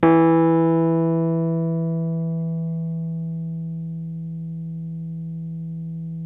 RHODES CL08R.wav